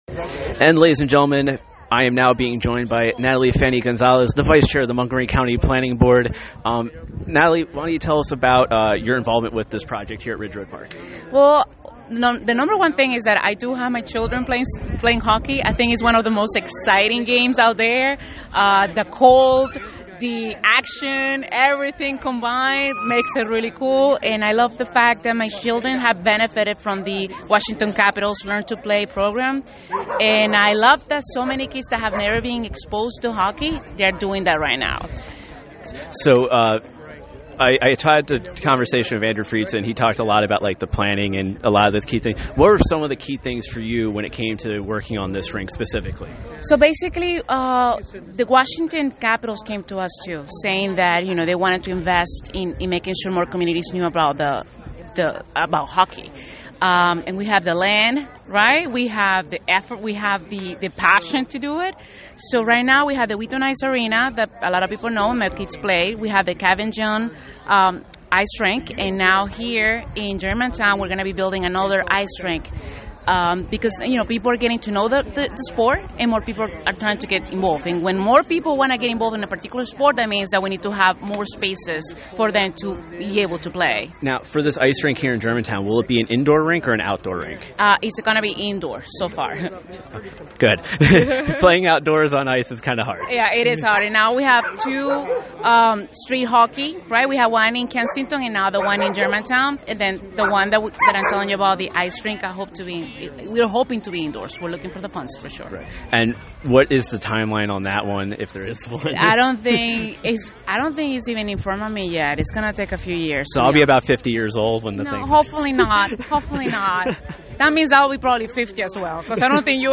During the event I was able to speak with some of the key people that helped make today possible, and here are our interviews with them.
Natali Fani-Gonzalez (Vice Chair, Montgomery County Planning Board)